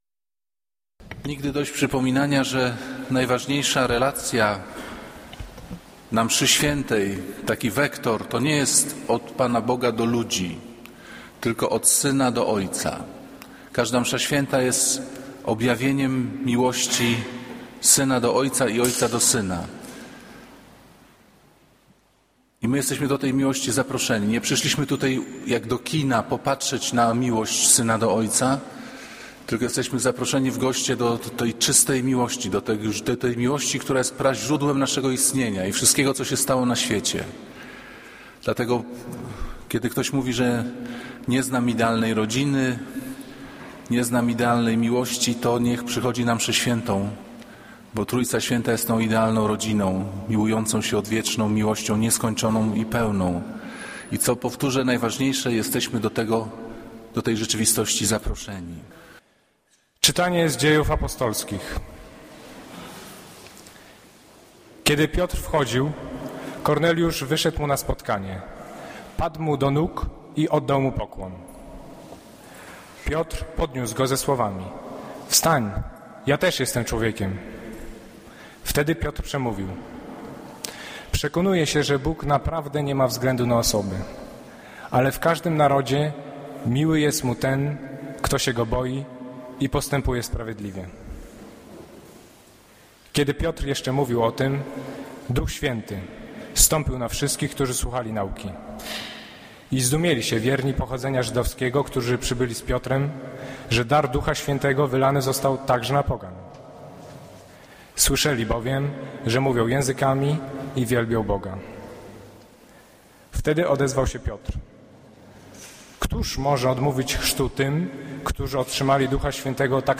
Kazanie z 13 maja 2012r.